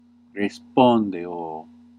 Ääntäminen
IPA : /rɪˈplaɪ/ IPA : /ɹɪˈplaɪ/